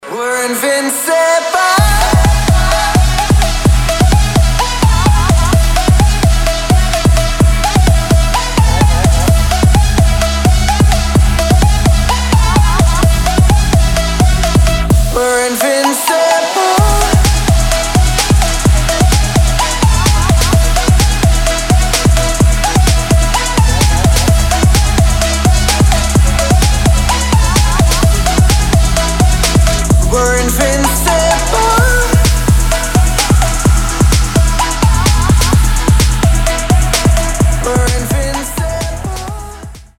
• Качество: 320, Stereo
мужской вокал
громкие
dance
Electronic
future bass